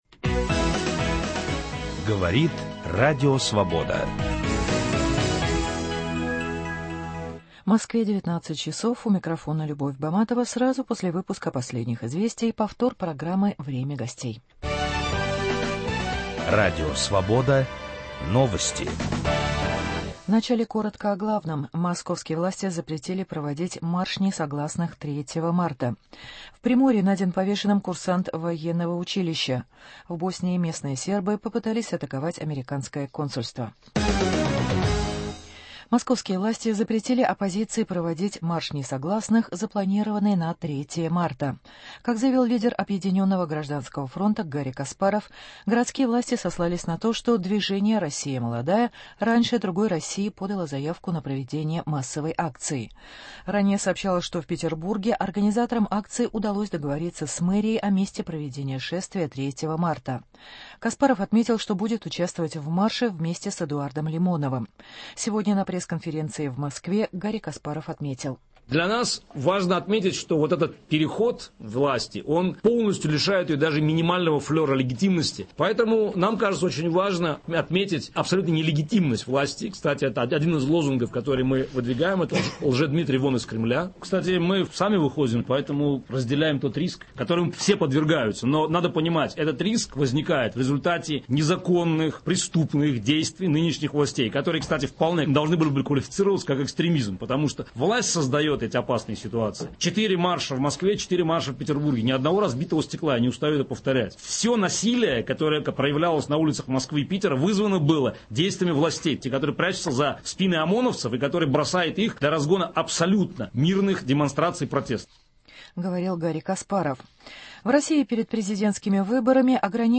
Разговор о диалоге власти и общества.